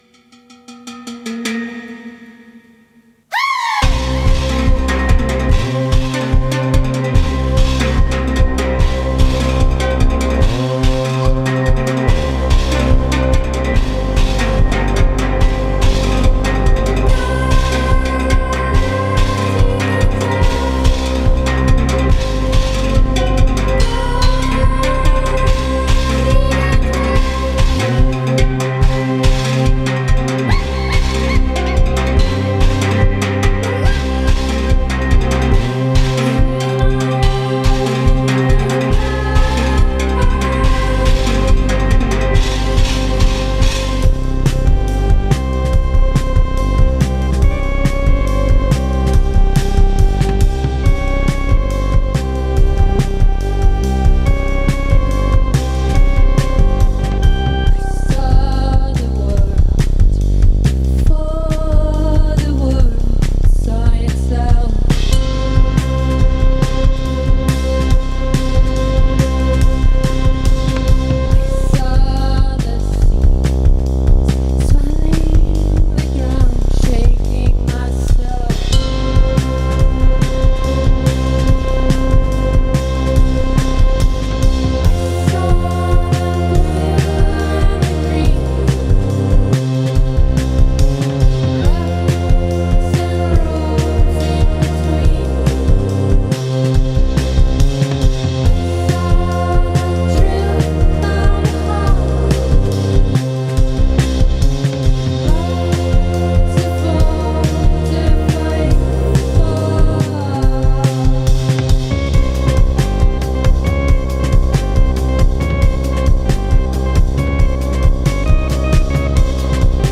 An endless sonic landscape and infinite sensory excursion.
The Amsterdam based duo
vivid, spiraling stories with their eerie, persistent songs